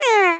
monster_sad.mp3